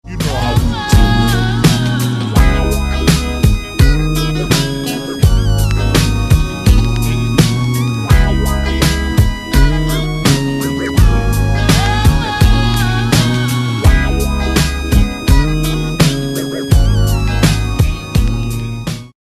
yum sound effects free download